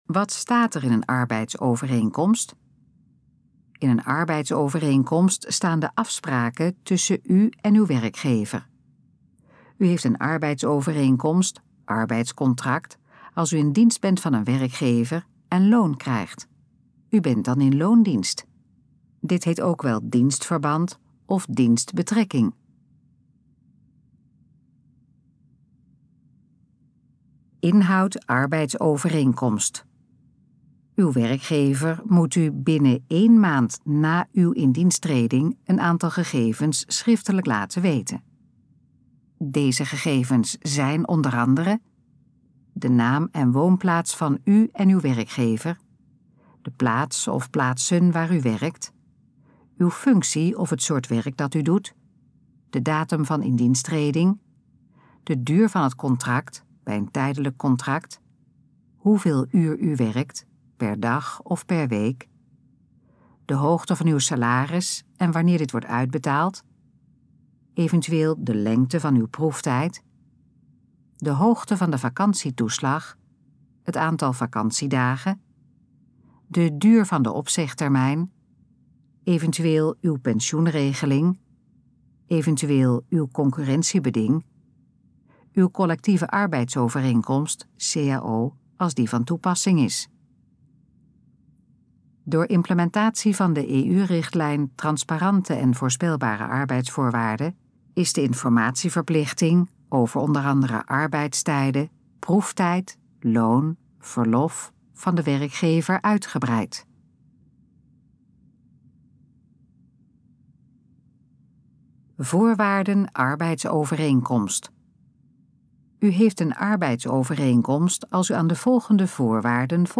Gesproken versie van Wat staat er in een arbeidsovereenkomst?